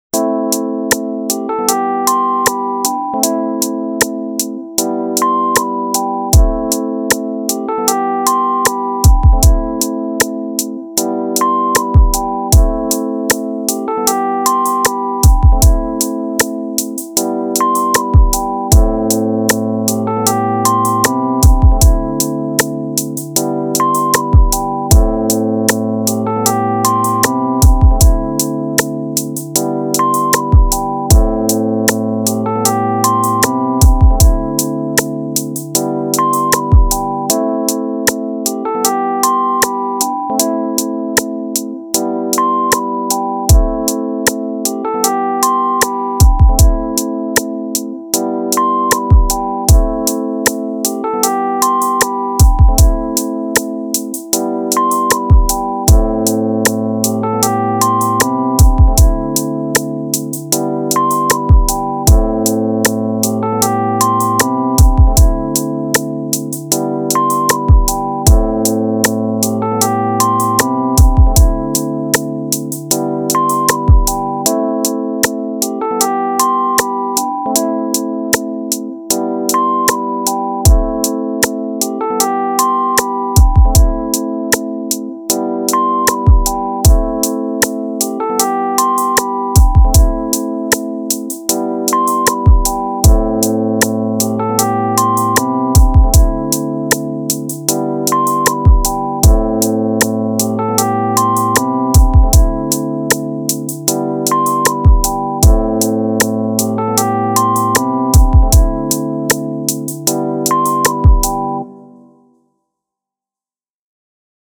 ORIGINAL INSTRUMENTALS